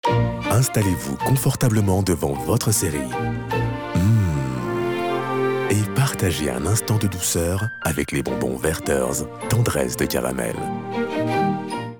Voix off
Voix - Basse